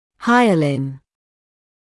[‘haɪəlɪn][‘хайалин]гиалин, гиалиновое вещество; гиалиновый, стекловидный